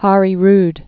(härē rd)